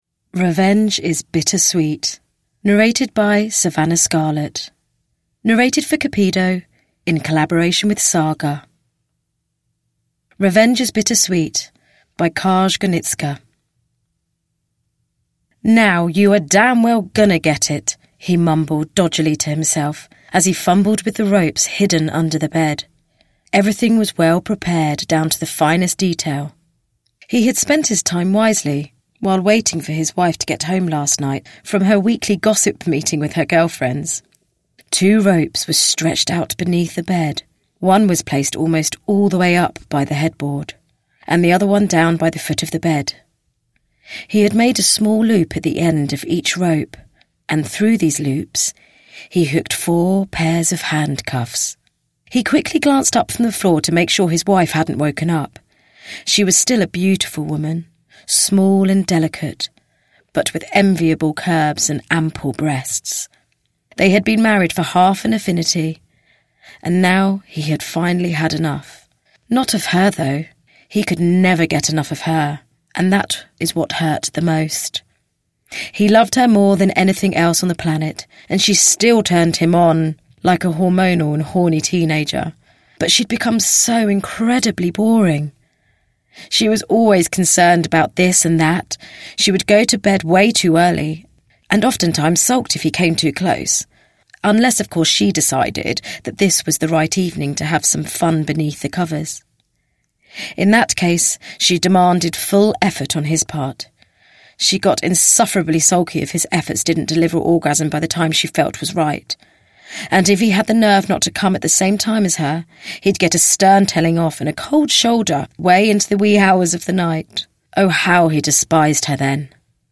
Revenge is Bittersweet (ljudbok) av Cupido